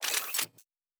Weapon 08 Reload 1 (Laser).wav